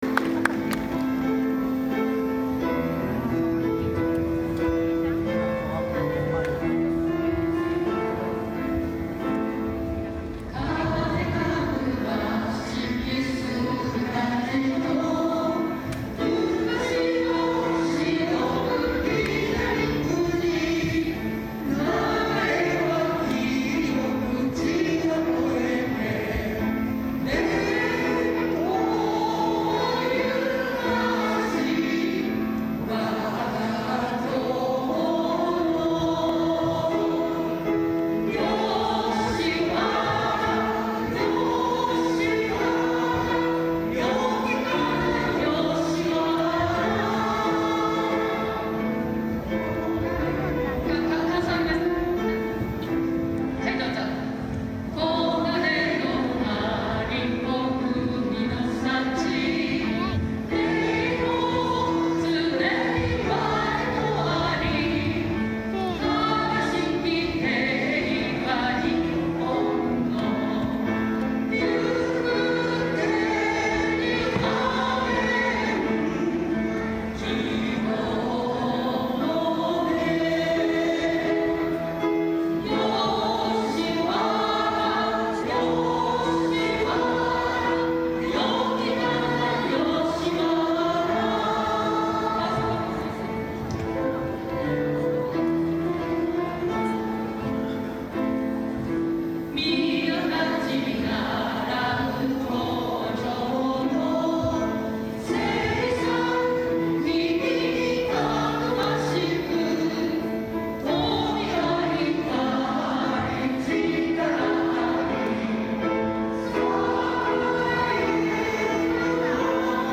平成28年10月30日、吉原地区文化祭において、吉原地区生涯学習推進会を中心とする地区の皆さんと吉原小学校4年生による合唱（吉原小学校体育館）が行われました。
目次 （資料）吉原市民歌（昭和32年制定）・躍進吉原の歌 吉原市民歌（昭和32年制定）・躍進吉原の歌 平成28年10月30日、吉原地区文化祭において、吉原地区生涯学習推進会を中心とする地区の皆さんと吉原小学校4年生による合唱（吉原小学校体育館）が行われました。
吉原市民歌 歌詞（PDF：73KB） 吉原市民歌 合唱（3分40秒）（MP3:8,619KB） 吉原市民課 楽譜（PDF：256KB） 躍進吉原の歌 歌詞（PDF：65KB） 躍進吉原の歌 合唱（3分10秒）（MP3:7,461KB） 躍進吉原の歌 楽譜（PDF：91KB）